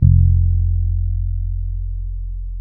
-MM DUB  F 2.wav